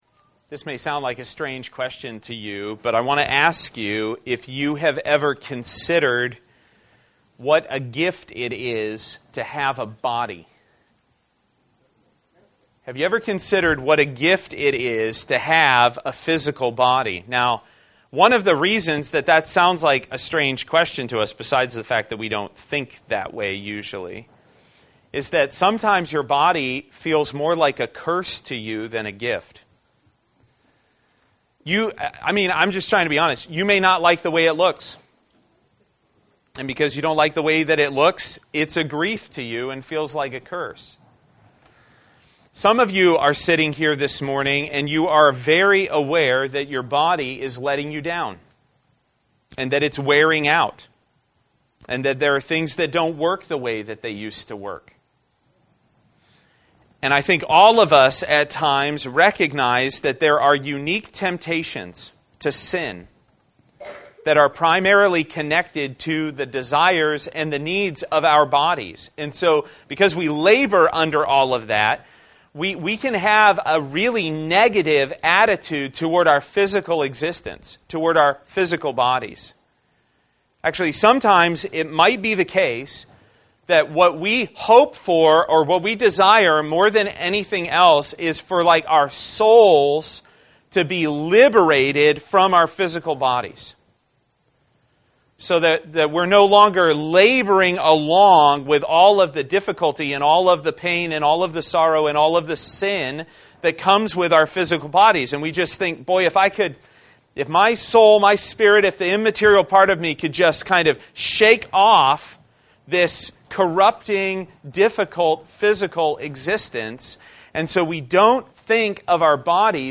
A message from the series "He Was Raised." I Corinthians 15:1-11